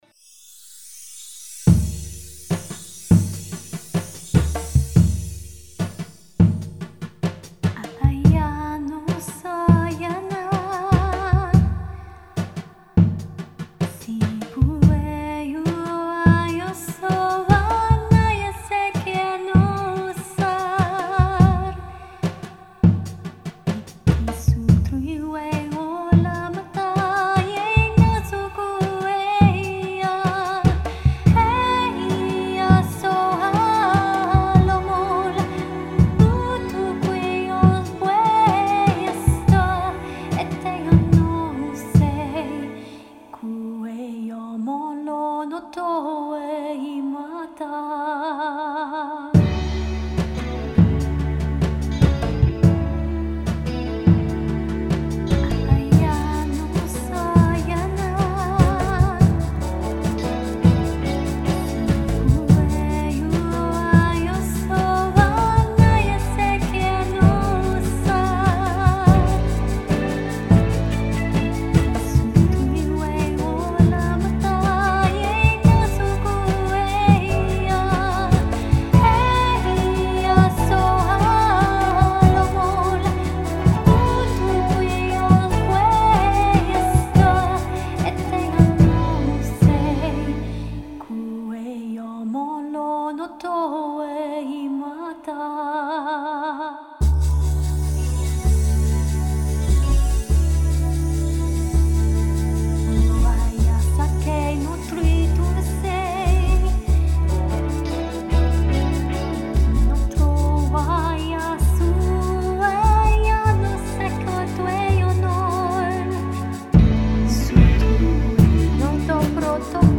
The links to songs below feature songs sung in light language, a language unique to its owner. With their permission I remotely recorded over the internet
singing acappella and then created a musical canvas behind them to complement their voices.